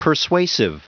Prononciation du mot persuasive en anglais (fichier audio)
Prononciation du mot : persuasive